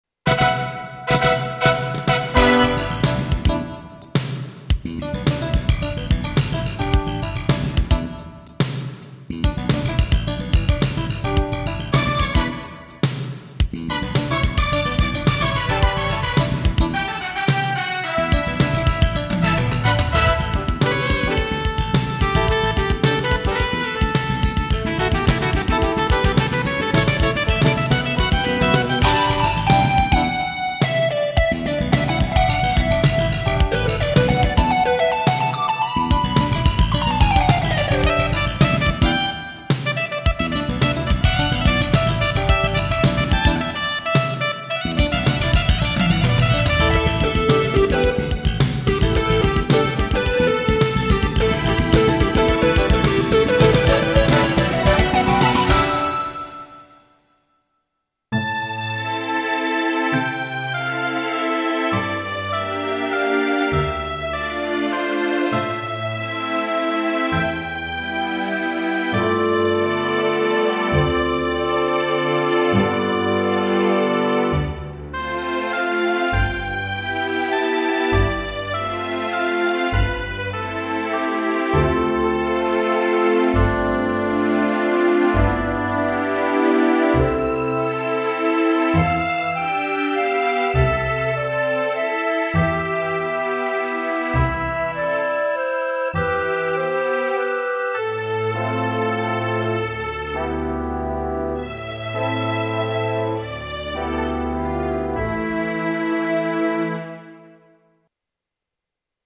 Roland SCB-55 (SCD-15) Daughterboard